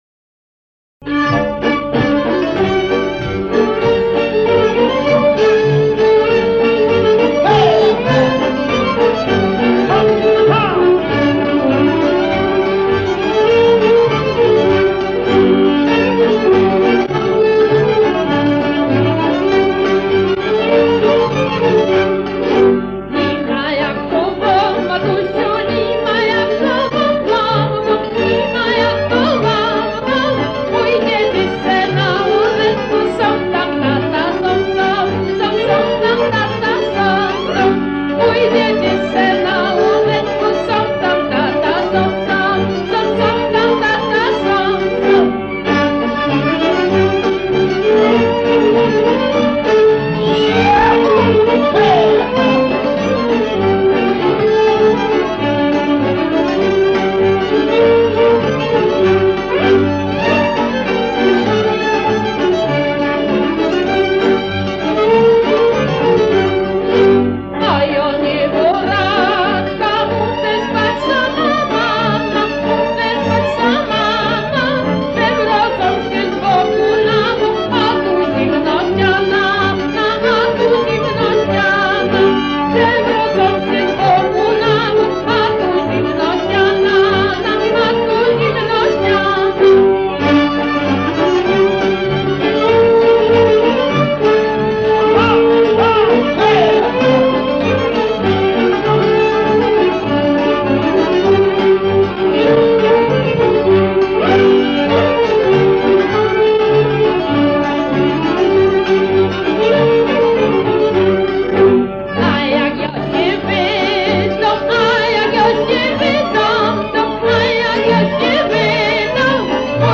Ponizej pliki dźwękowe mp3 z e zbiorów Muzeum Etnograficznego w Rzeszowie.